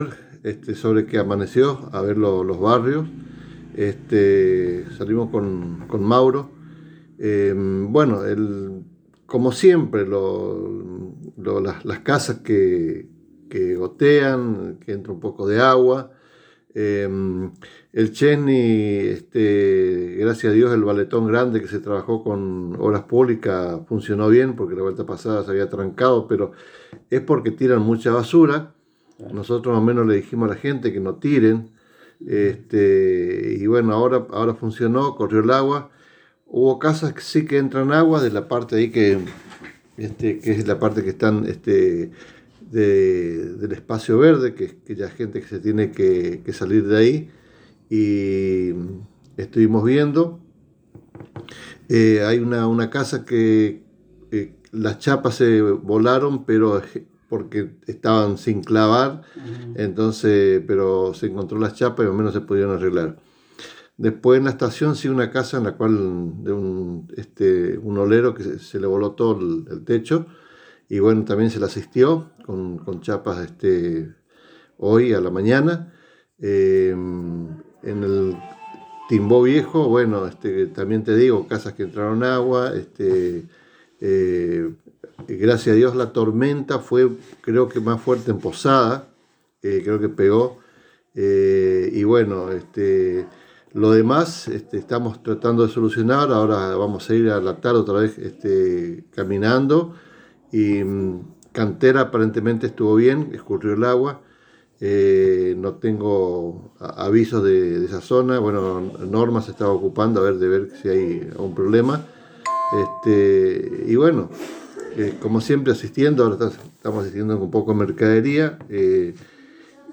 El Secretario de Desarrollo Social Municipal de Apóstoles, Alberto «Nene» Sotelo, en diálogo exclusivo con la ANG, manifestó que en la madrugada que sucedió la tormenta de viento y agua en la ciudad, salieron inmediatamente con sus colaboradores a ver la situación de los vecinos, especialmente en los barrios de escasos recursos y así brindar la ayuda necesaria.